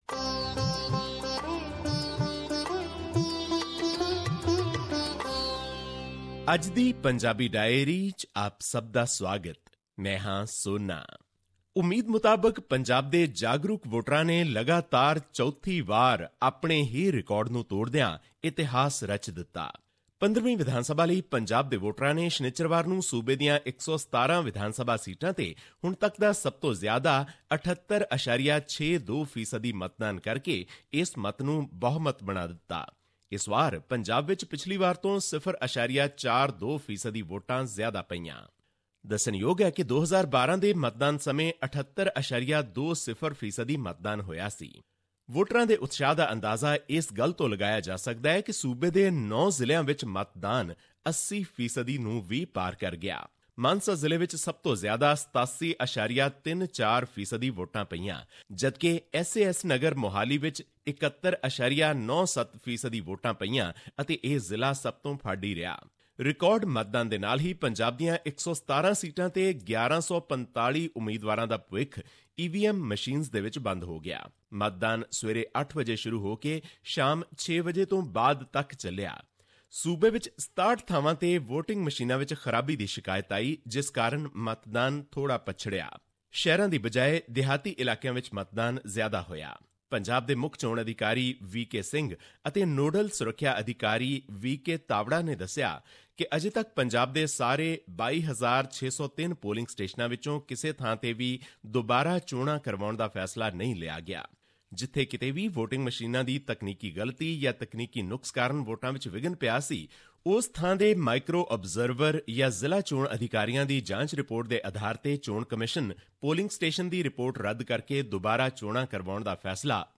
His report was presented on SBS Punjabi program on Monday, Feb 06, 2017, which touched upon issues of Punjabi and national significance in India. Here's the podcast in case you missed hearing it on the radio.